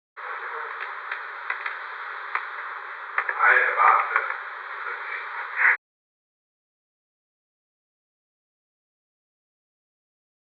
Secret White House Tapes
Conversation No. 448-15
Location: Executive Office Building
The President met with an unknown man